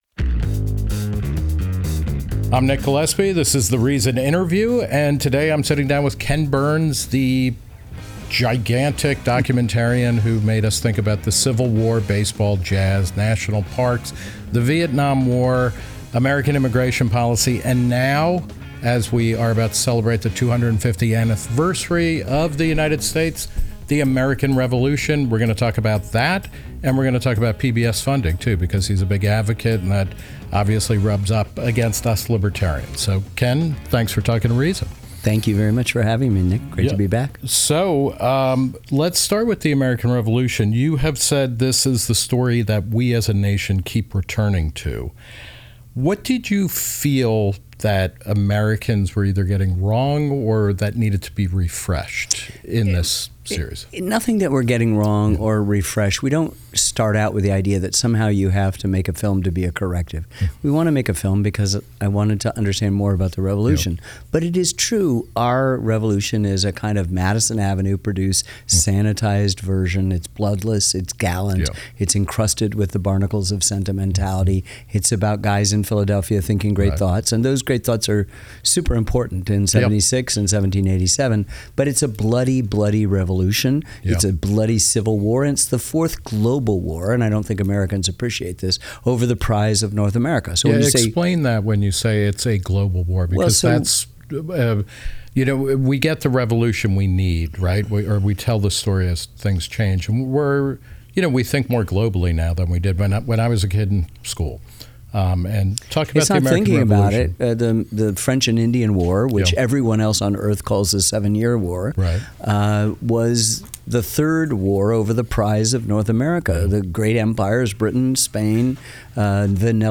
Filmmaker Ken Burns joins The Reason Interview to break down the myths surrounding America’s founding and the Declaration of Independense.